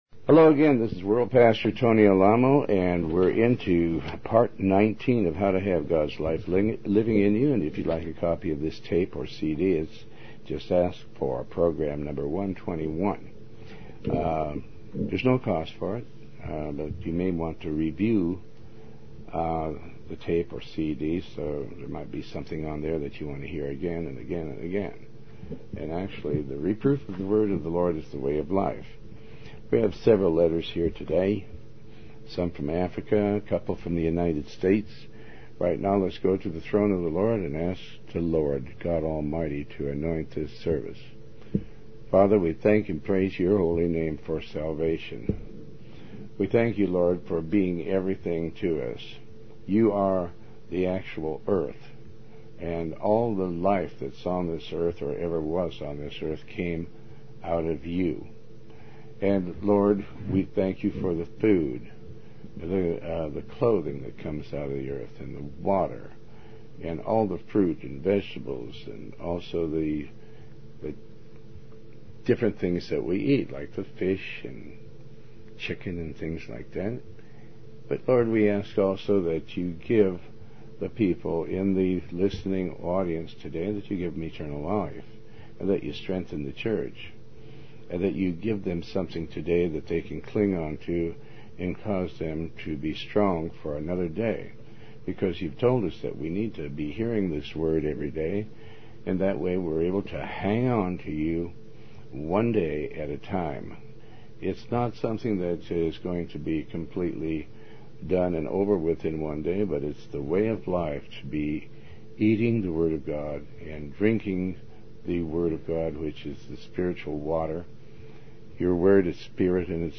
Tony Alamo Talk Show